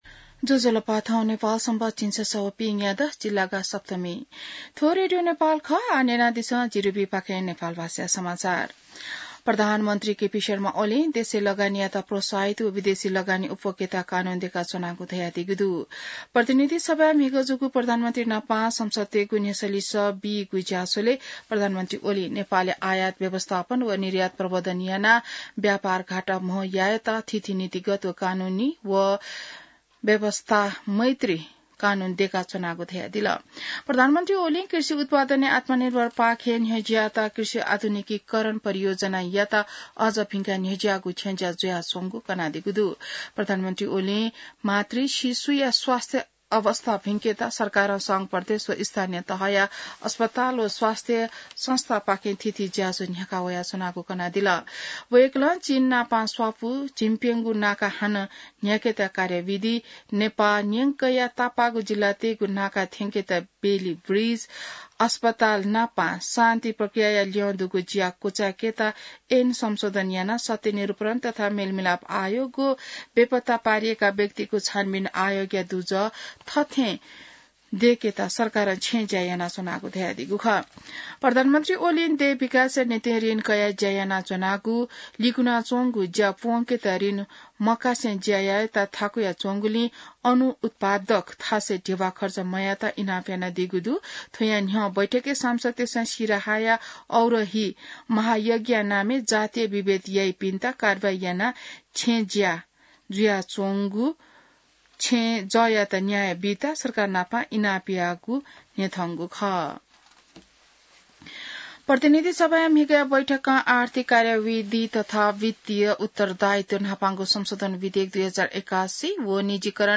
नेपाल भाषामा समाचार : ८ चैत , २०८१